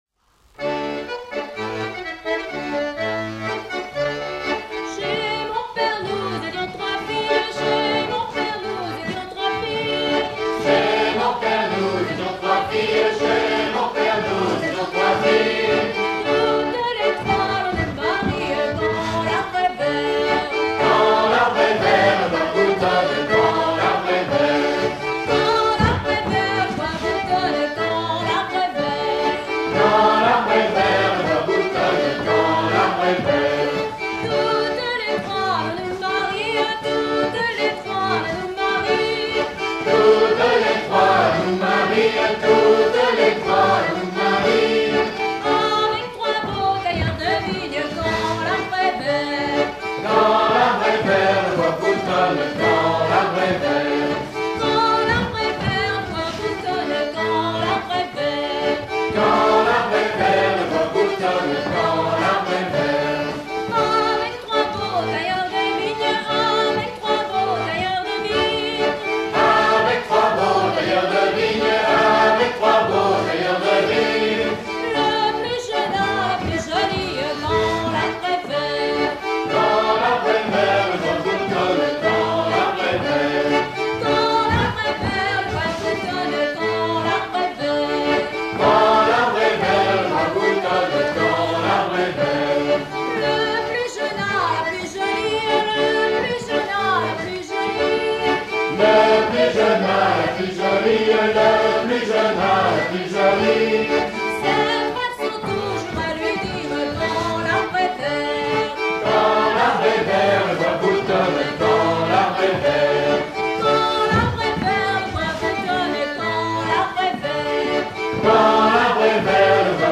danse : ronde à la mode de l'Epine
Genre laisse
Dix danses menées pour des atelirs d'apprentissage
Pièce musicale inédite